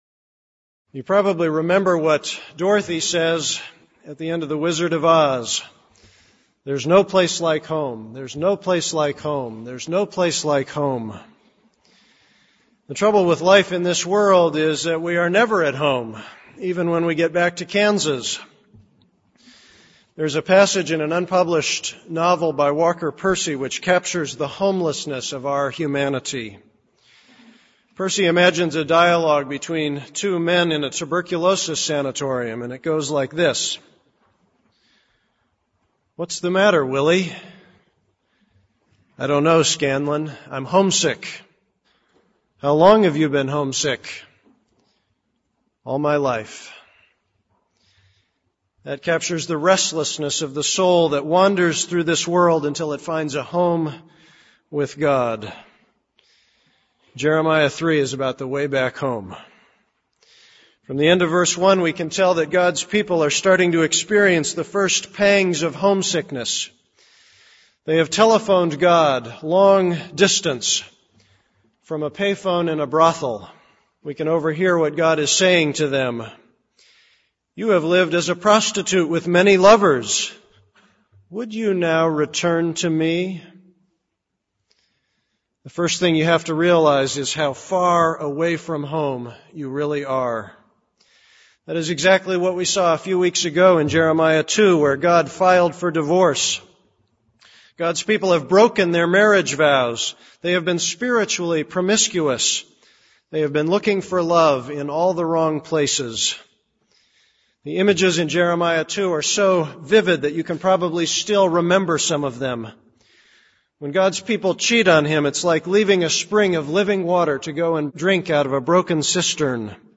This is a sermon on Jeremiah 3:1-18.